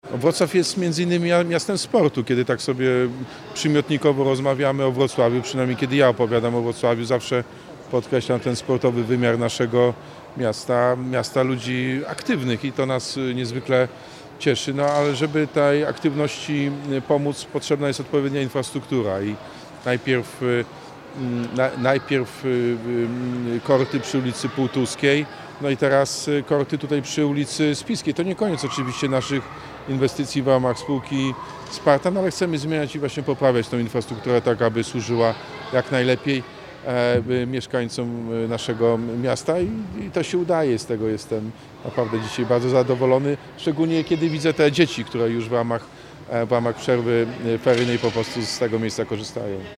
Prezydent Wrocławia zaznacza, że korty przy ulicy Spiskiej to kolejny krok w rozwoju infrastruktury sportowej miasta.